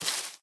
sounds / block / cherry_leaves / step5.ogg
step5.ogg